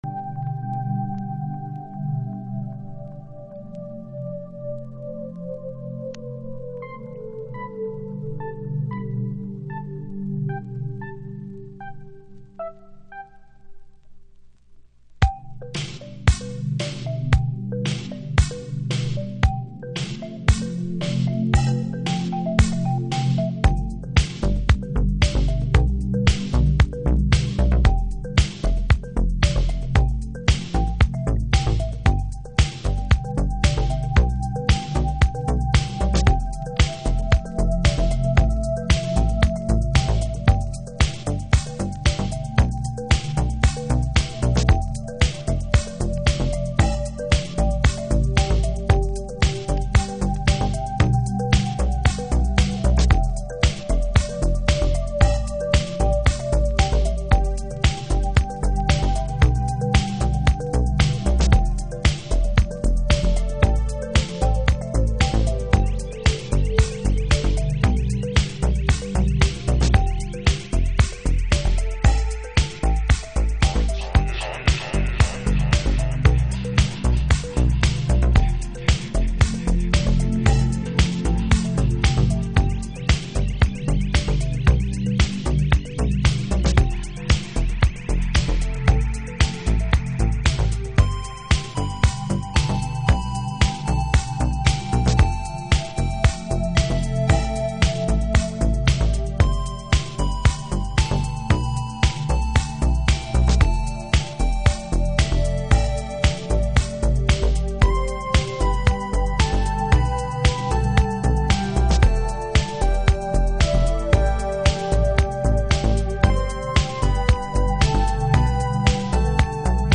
まるでマイクオールドフィールドやジャンミッシェルジャールを彷彿させるトラックは当時のハウスシーンでも異形だったハズ。